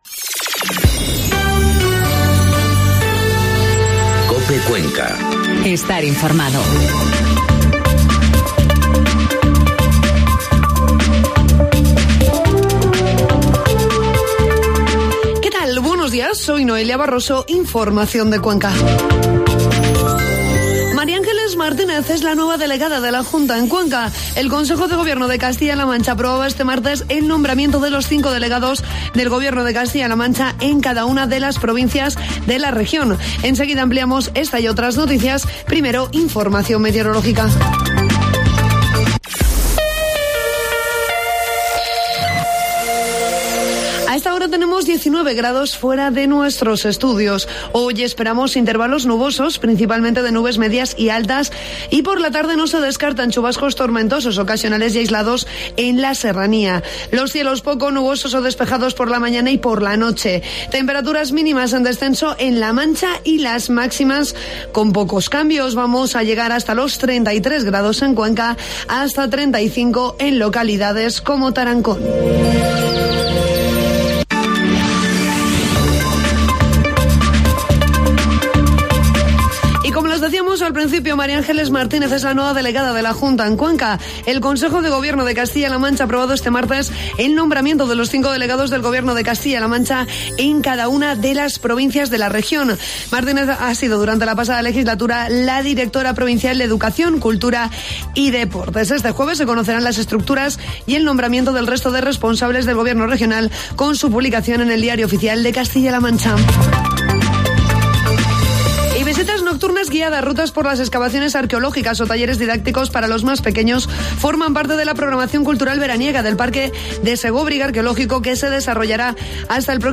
Informativo matinal COPE Cuenca 17 de julio